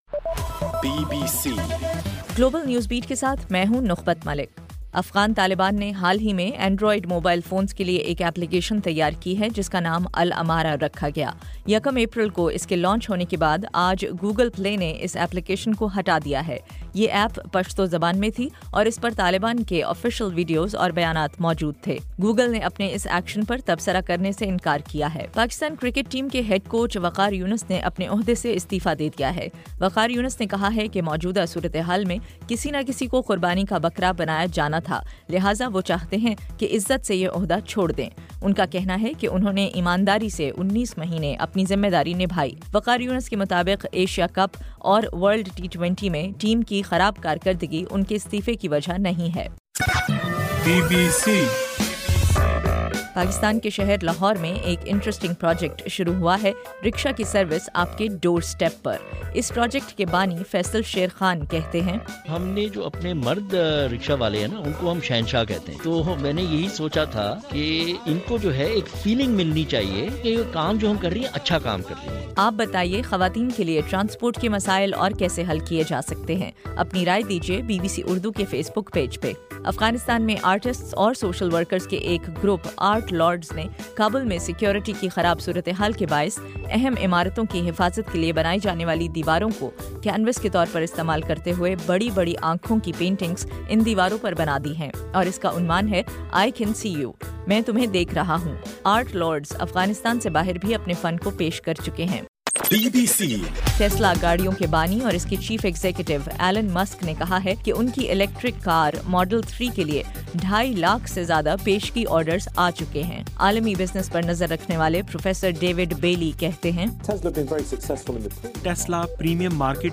گلوبل نیوز بیٹ بُلیٹن اُردو زبان میں رات 8 بجے سے صبح 1 بجے تک ہر گھنٹے کے بعد اپنا اور آواز ایف ایم ریڈیو سٹیشن کے علاوہ ٹوئٹر، فیس بُک اور آڈیو بوم پر ضرور سنیے۔